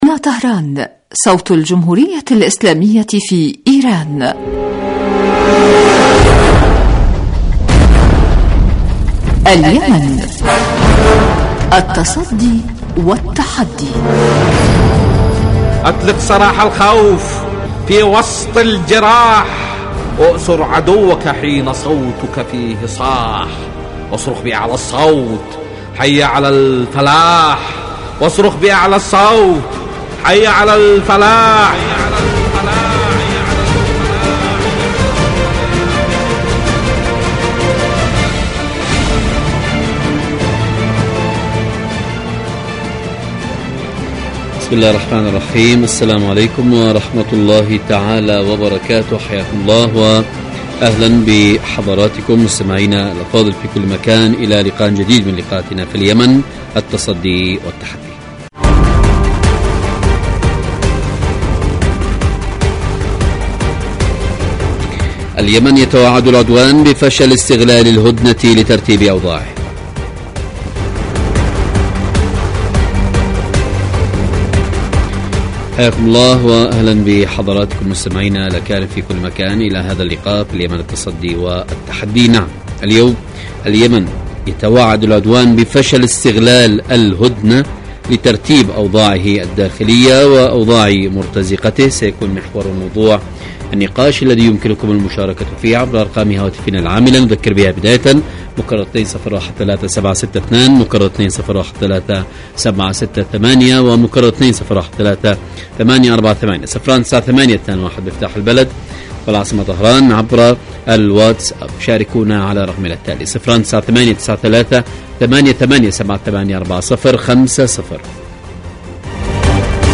برنامج سياسي حواري يأتيكم مساء كل يوم من إذاعة طهران صوت الجمهورية الإسلامية في ايران
البرنامج يتناول بالدراسة والتحليل آخر مستجدات العدوان السعودي الأمريكي على الشعب اليمني بحضور محللين و باحثين في الاستوديو.